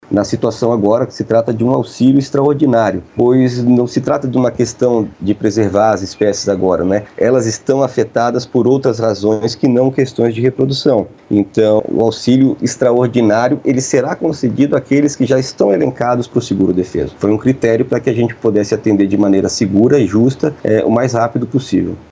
De acordo com Carlos Melo, Secretário Executivo do Ministério da Pesca e Aquicultura, diferente do seguro defeso, o auxilio extraordinário é pago aos pescadores que ficaram sem trabalhar durante a seca mais severa dos últimos 120 anos.